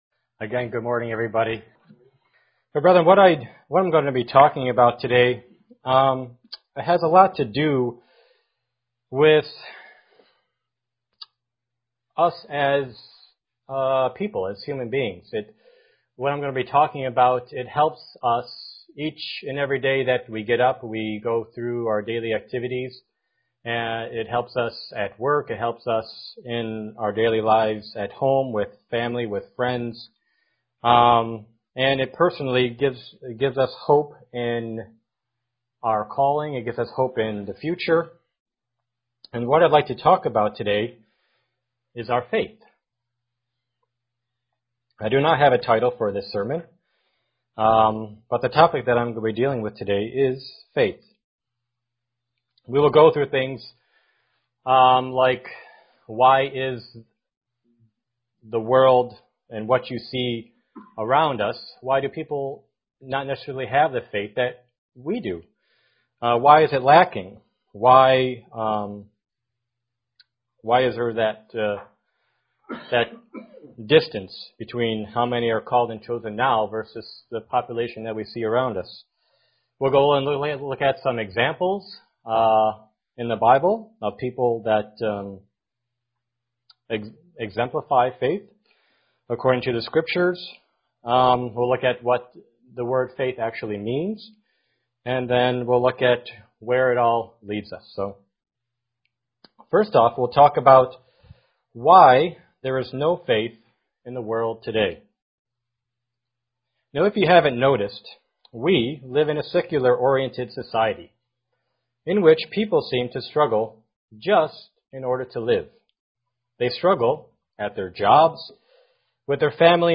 Given in Elmira, NY
UCG Sermon